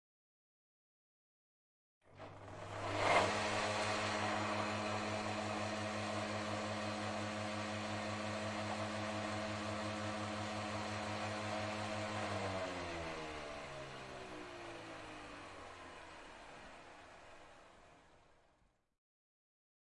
木质滑动台锯机
对声音进行了后处理，以消除任何房间音调和背景噪音。用Zoom H6录音机和X / Y胶囊录制。